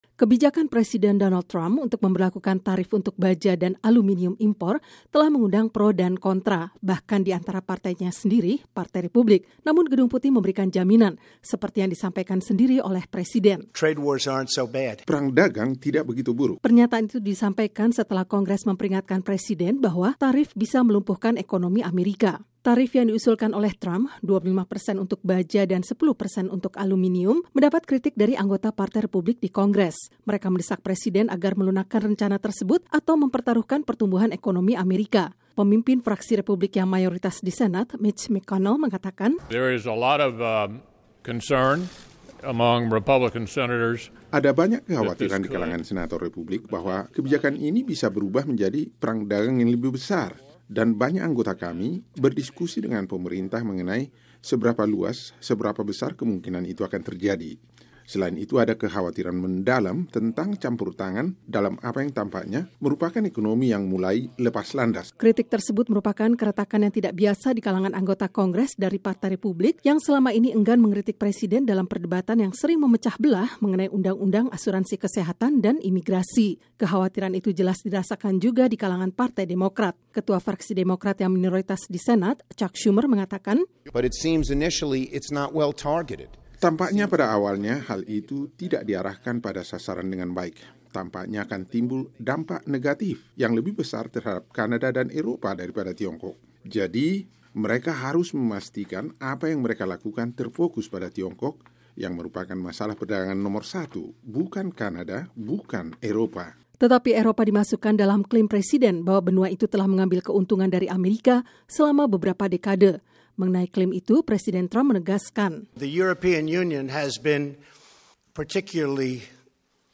CAPITOL HILL —